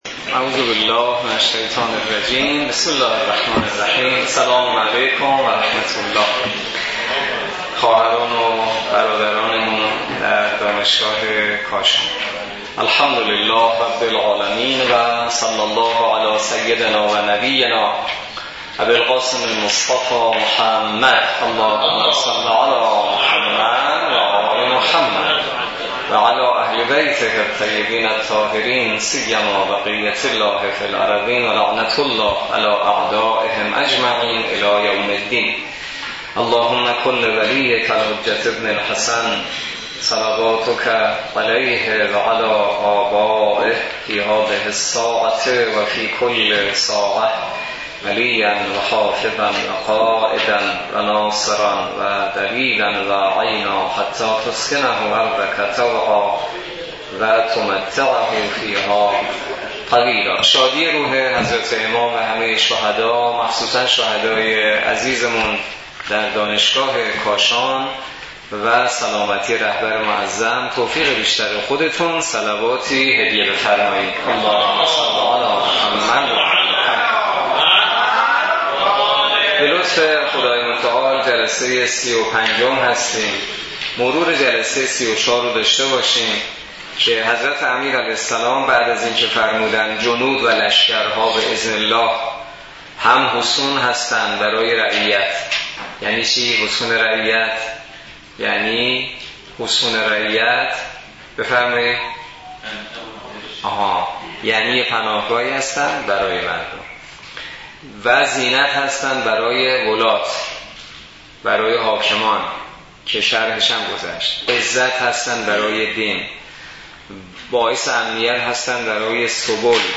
برگزاری سی و پنجمین جلسه تفسیر نامه ۵۳ نهج البلاغه توسط نماینده محترم ولی فقیه و در دانشگاه کاشان
سی و پنجمین جلسه تفسیر نامه ۵۳ نهج البلاغه توسط حجت‌الاسلام والمسلمین حسینی نماینده محترم ولی فقیه و امام جمعه کاشان در دانشگاه کاشان برگزار گردید.